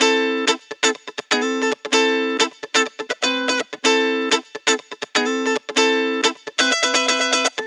Electric Guitar 14.wav